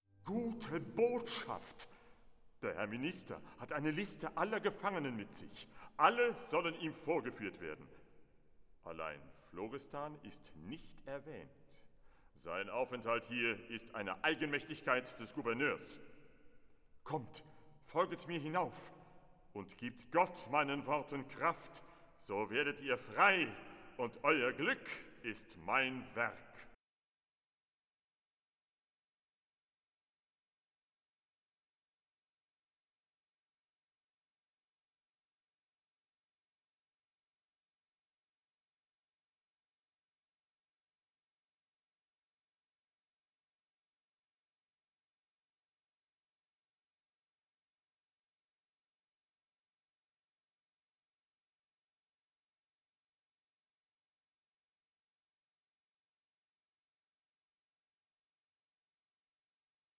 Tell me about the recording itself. in two studio sessions